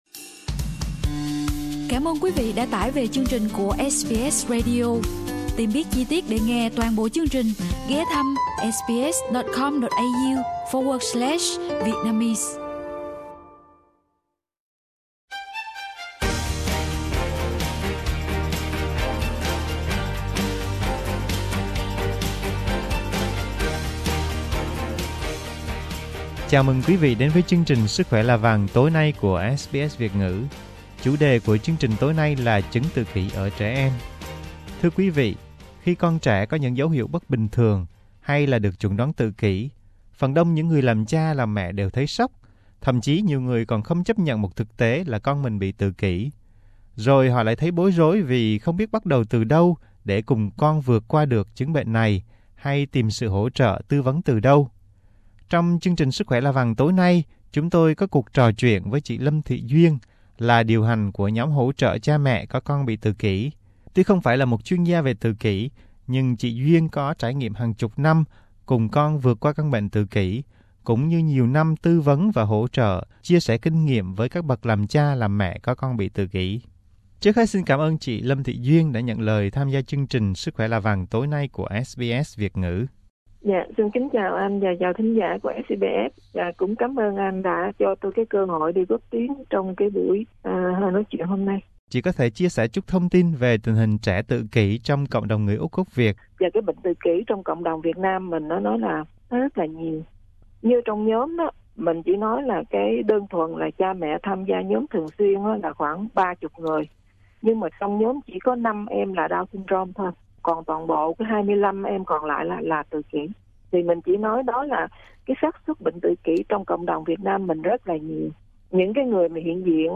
Sức khỏe là vàng có cuộc trò chuyện